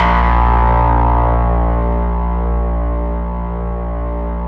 RESMET A#1-L.wav